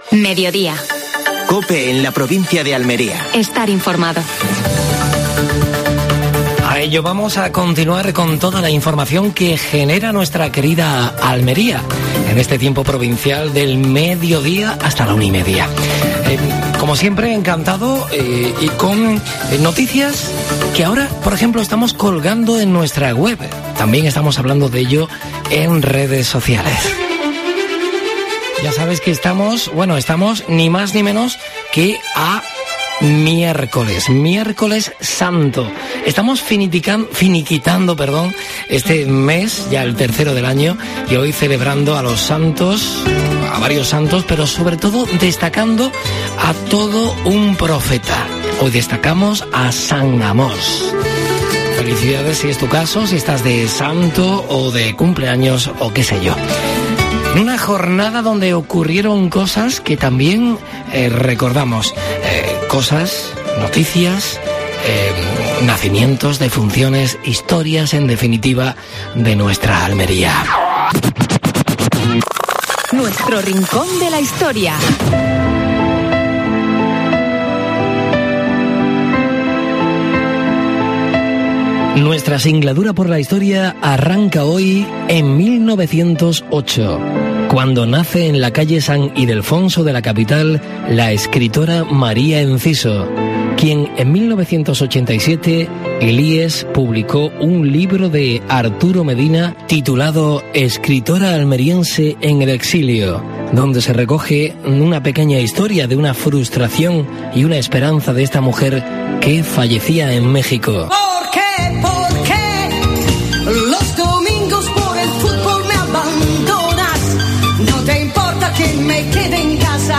AUDIO: Actualidad en Almería. Entrevista a Juan de la Cruz Belmonte (delegado de Salud de la Junta de Andalucía en la provincia).